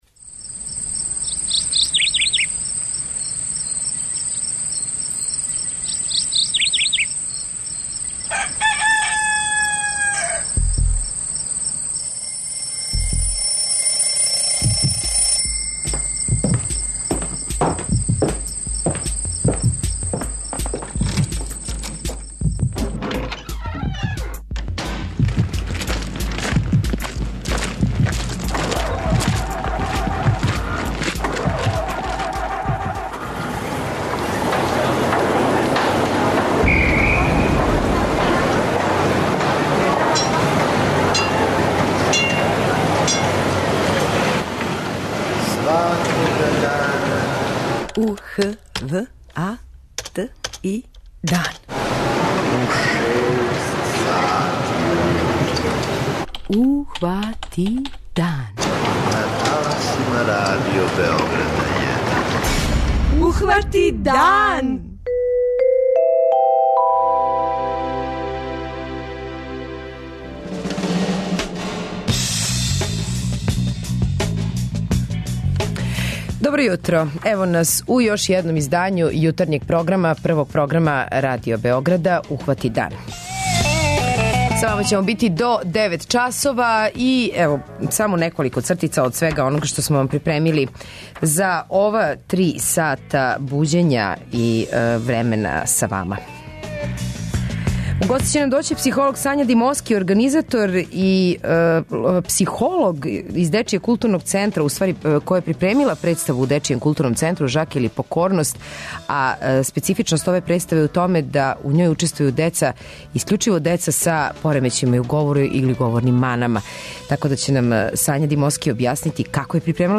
Овог јутра разговарамо са државним секретаром у Министарству трговине, туризма и телекомуникација, Татјаном Матић.
преузми : 85.87 MB Ухвати дан Autor: Група аутора Јутарњи програм Радио Београда 1!